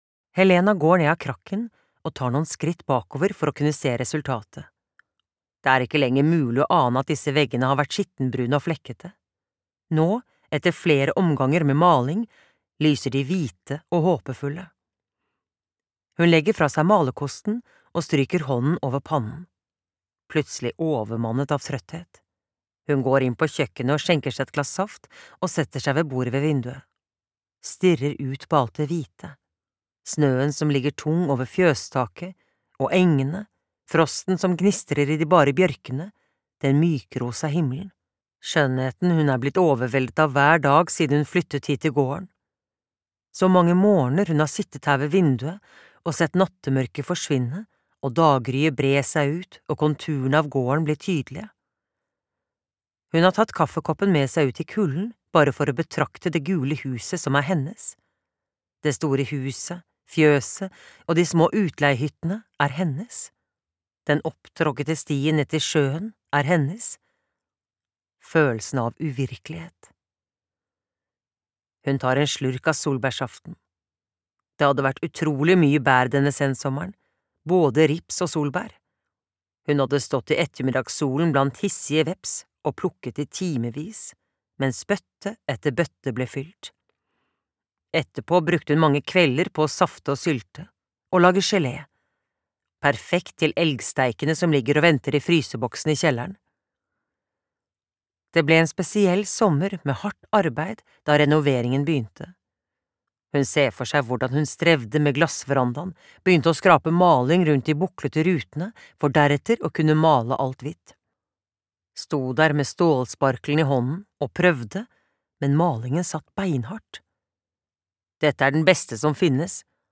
Pianisten ved fjellvannet (lydbok) av Karin Härjegård